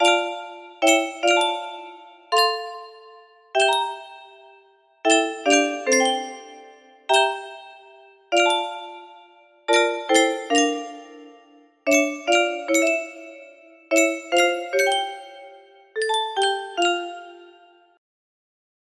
Man idk music box melody